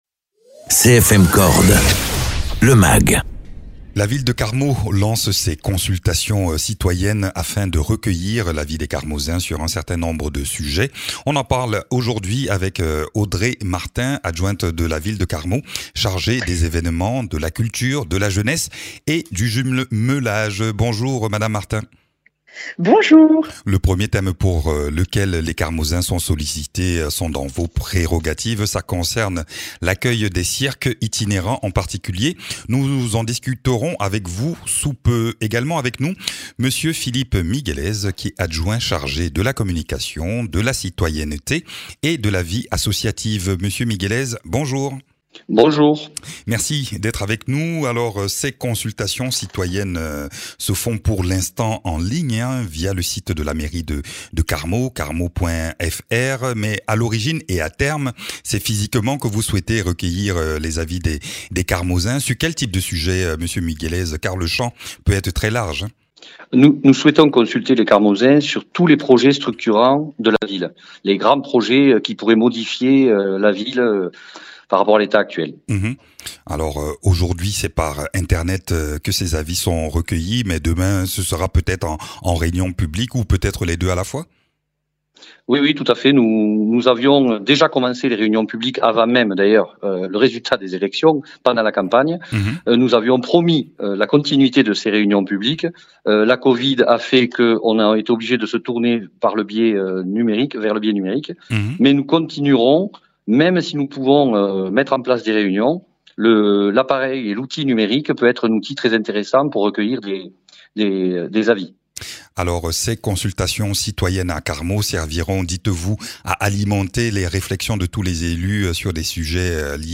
Interviews
Les habitants de Carmaux sont invités à donner leur avis sur des projets structurants concernant la commune. On en parle aujourd’hui dans ce magazine avec deux élus de l’équipe municipale.
Invité(s) : Philippe Miguelez, adjoint de la mairie de Carmaux à la communication, la citoyenneté et la vie associative ; Mme Audrey Martin, adjointe de la mairie de Carmaux aux événements, la culture, jeunesse et jumelage.